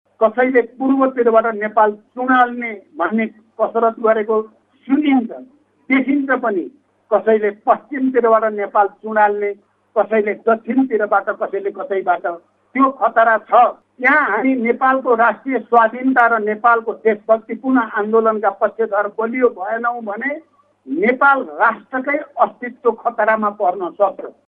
रुकुम पश्चिमको बाँफिकोट गाउँपालिका–६ मग्गामा पुगेर प्रचण्डले कसैले पूर्वतिरबाट कसैले पश्चिमतिरबाट नेपालको भूगोललाई हडप्ने प्रयत्न गरिरहेको आरोप लगाए।